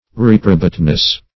Reprobateness \Rep"ro*bate*ness\